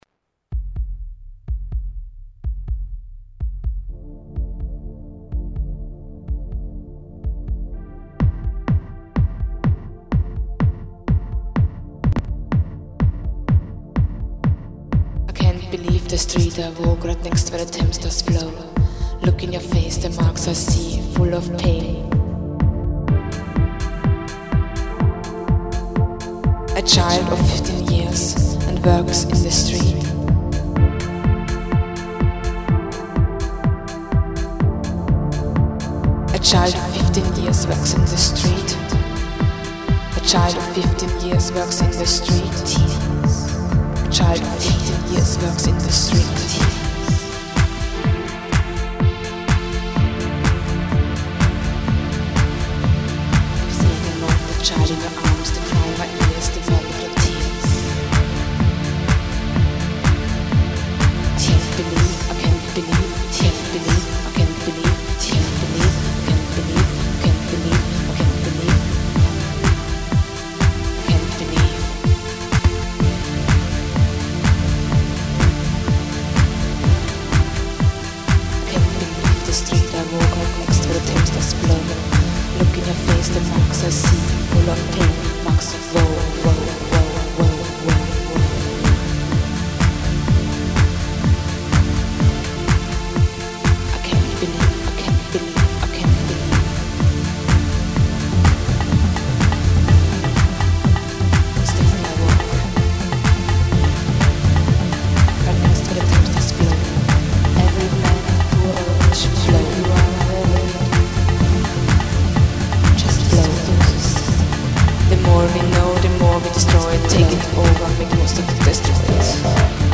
Glow (Experimental) 5.